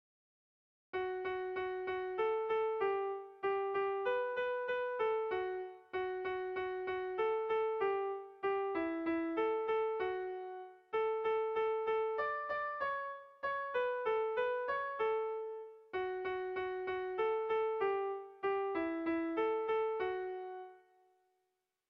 Kontakizunezkoa
Zortziko txikia (hg) / Lau puntuko txikia (ip)
A1A2BA2